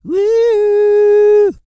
E-CROON 3042.wav